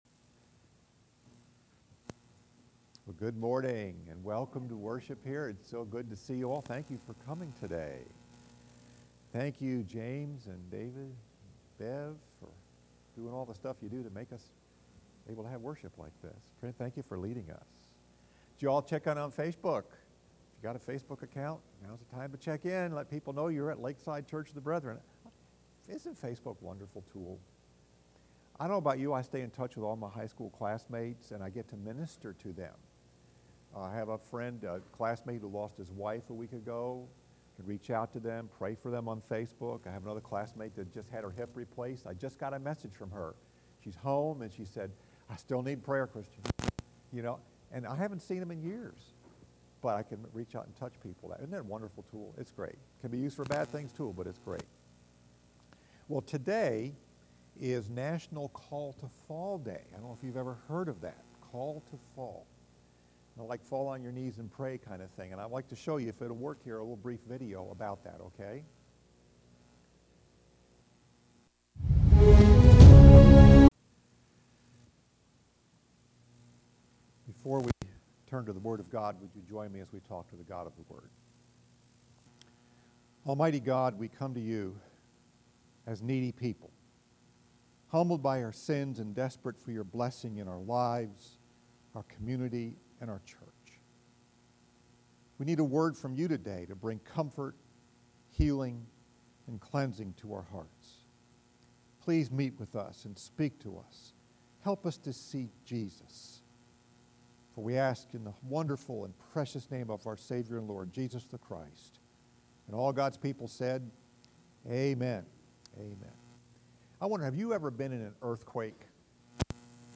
Message: “Keys to Powerful Prayer” – Part 1 Scripture: Acts 4:8-33